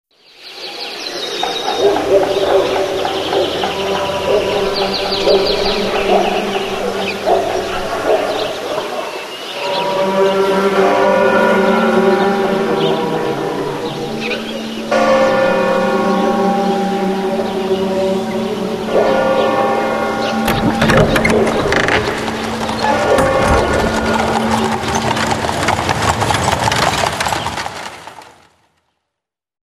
AMBIENTE NATURAL
Ambient sound effects
ambiente_natural.mp3